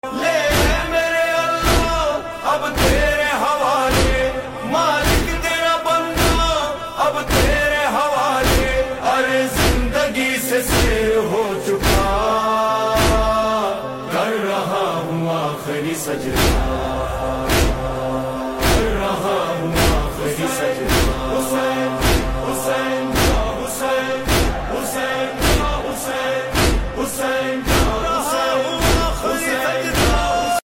یوم عاشور مرکزی ماتمی جلوس جھڈو